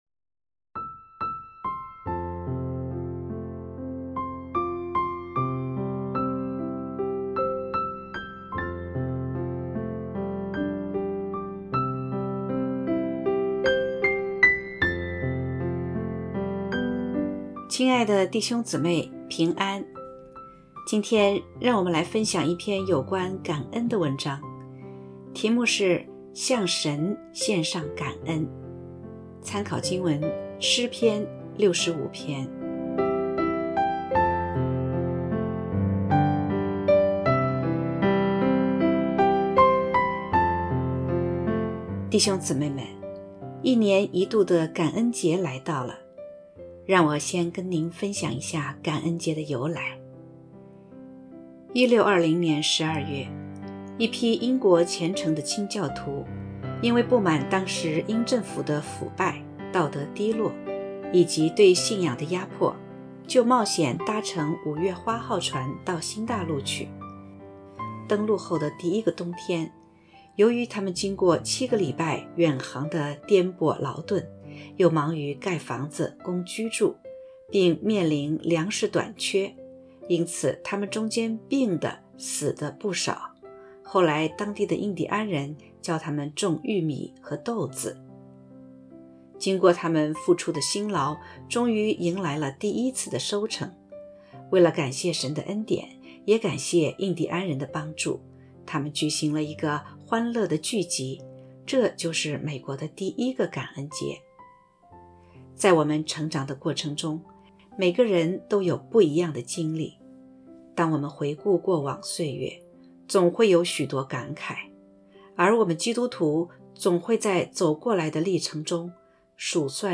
（合成）X向神献上感恩.mp3